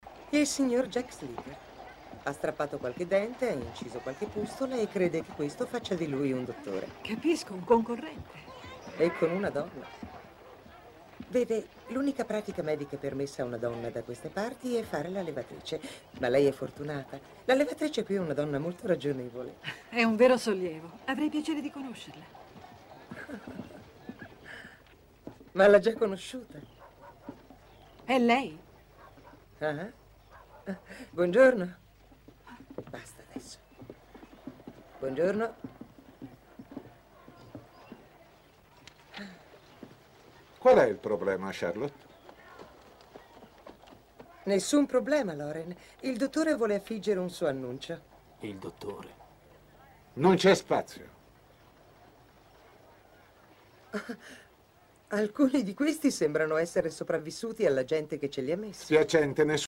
nel telefilm "La signora del West", in cui doppia Diane Ladd.